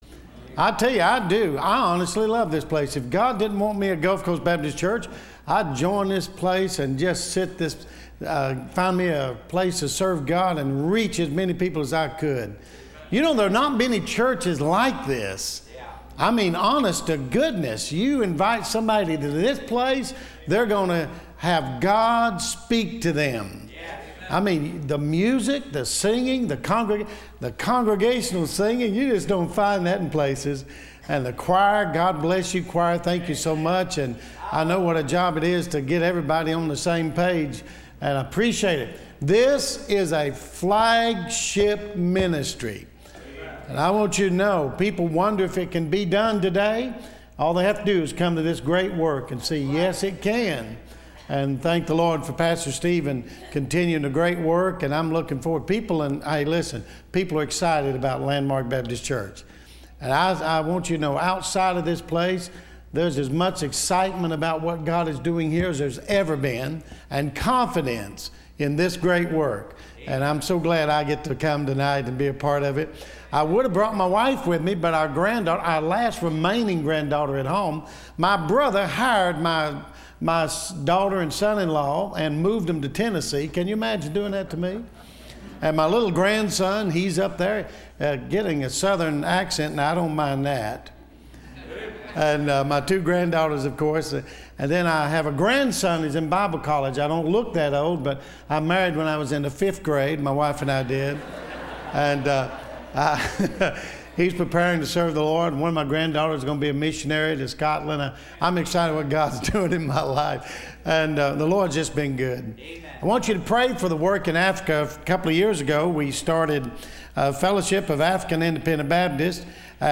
Listen to Message